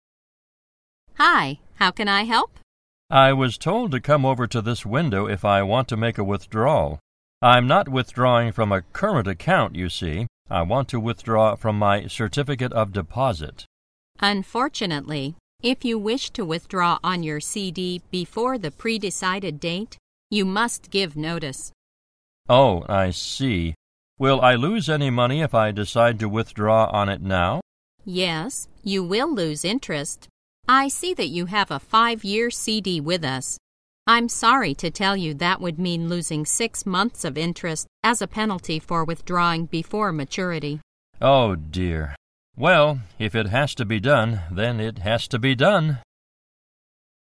在线英语听力室银行英语情景口语 第15期:现金业务 预支情景(3)的听力文件下载, 《银行英语情景口语对话》,主要内容有银行英语情景口语对话、银行英语口语、银行英语词汇等内容。